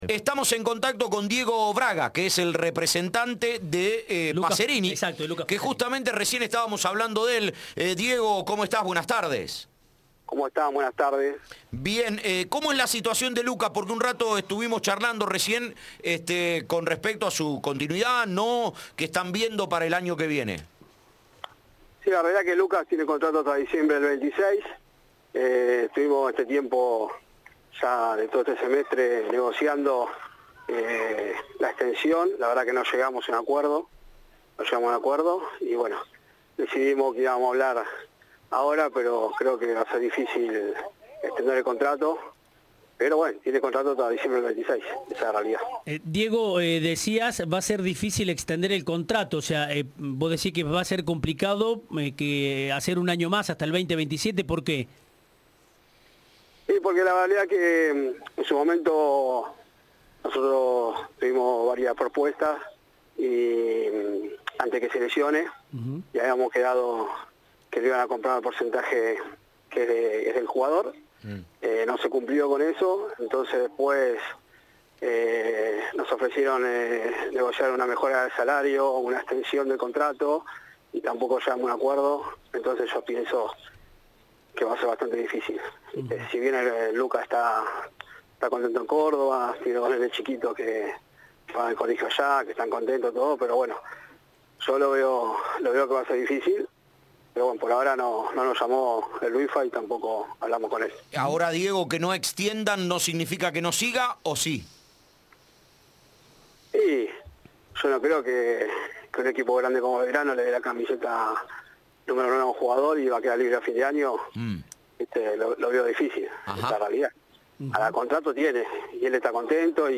Entrevista de La Cadena del Gol.